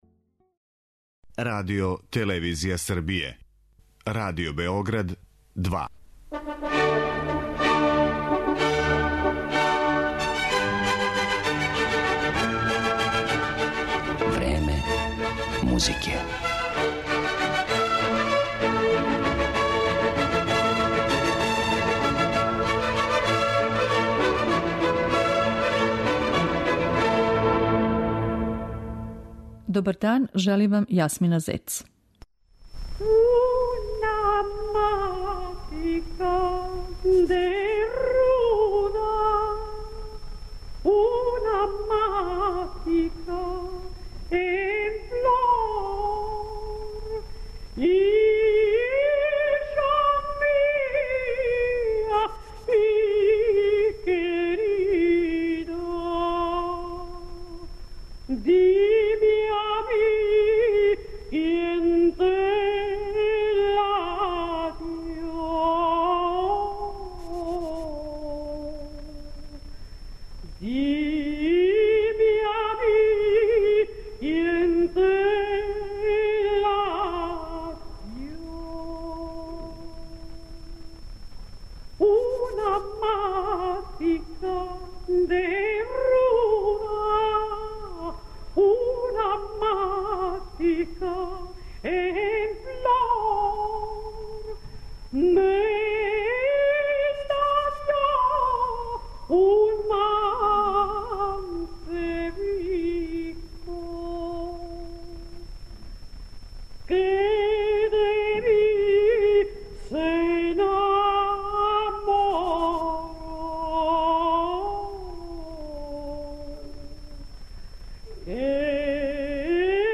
Један сегмент из њеног богатог репертоара моћи ће да чују и слушаоци Радио Београда 2.